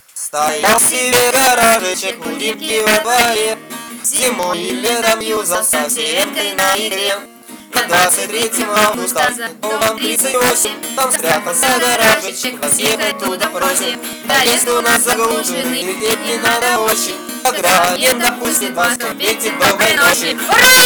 и песенка про гараж: